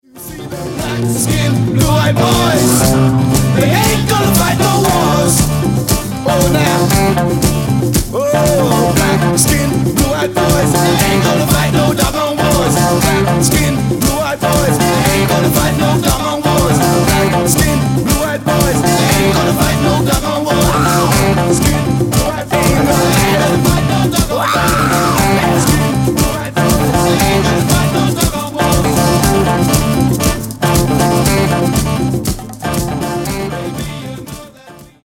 Genere:   Disco | Funky | Soul |